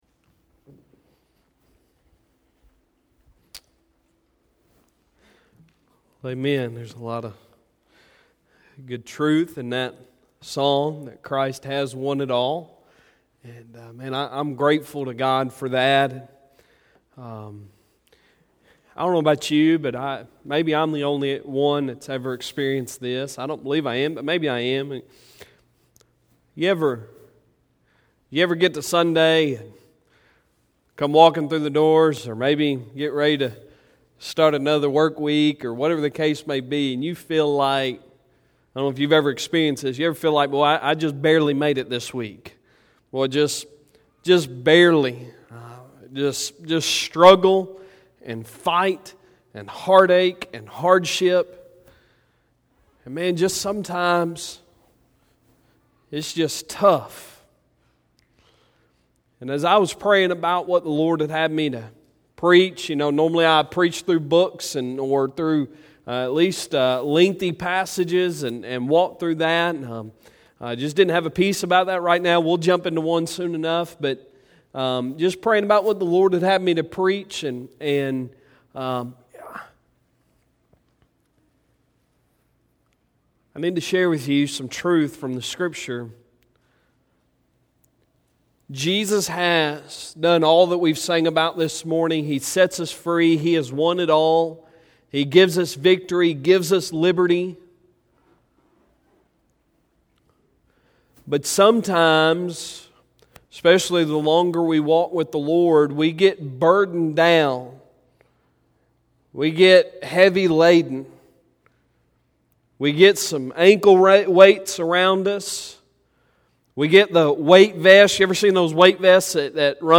Sunday Sermon October 27, 2019